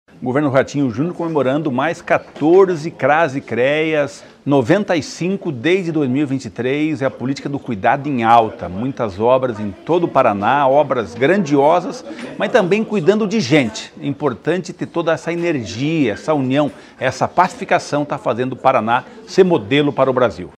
Sonora do secretário do Desenvolvimento Social e Família, Rogério Carboni, sobre o repasse de R$ 16,8 milhões para construção de CRAS e CREAS